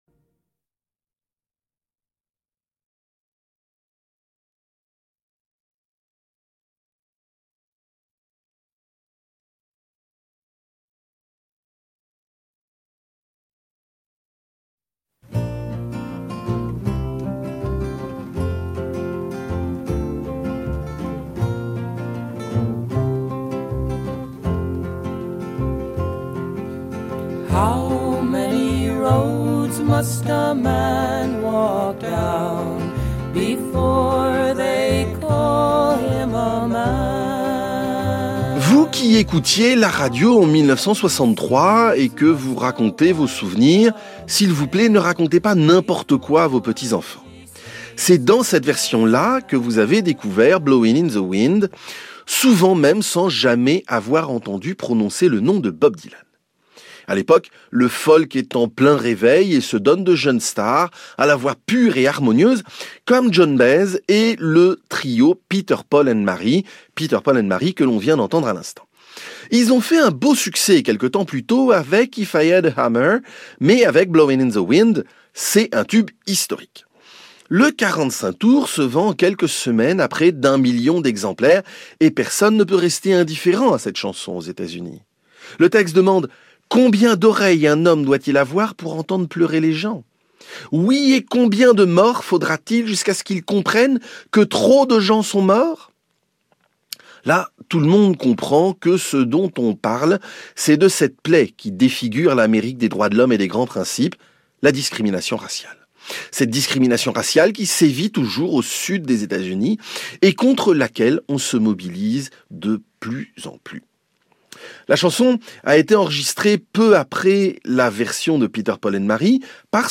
Les chroniques
diffusées sur France Info